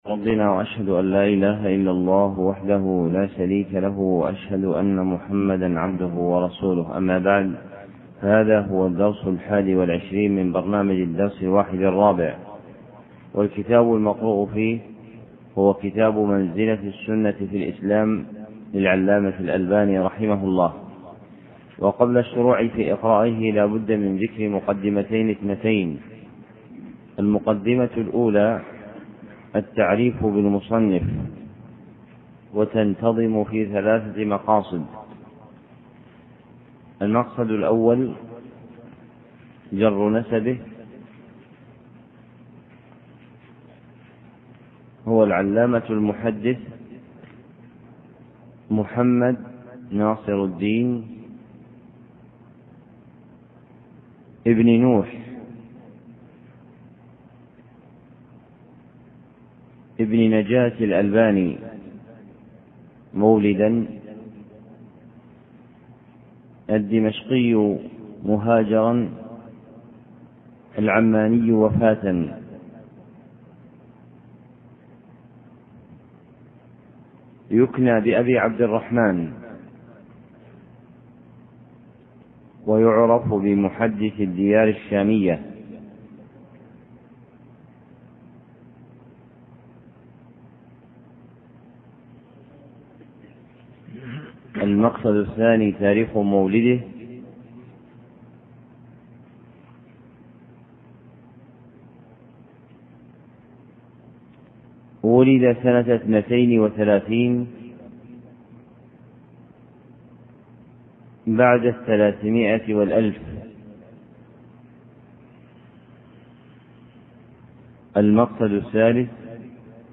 محاضرة صوتية نافعة،